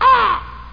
animals
crow.mp3